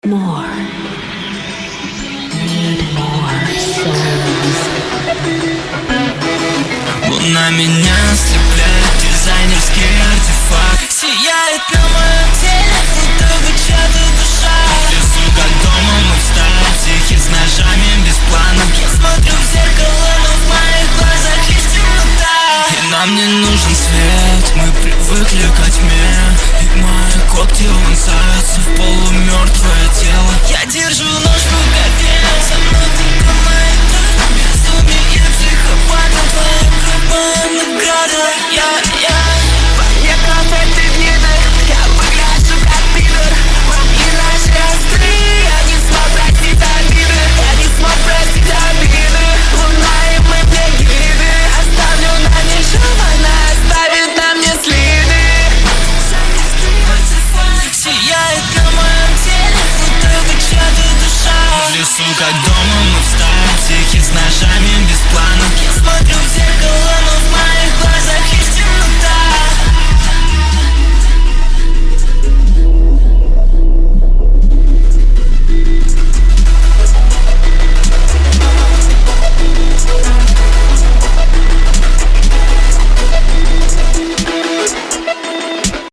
Рэп, Современная музыка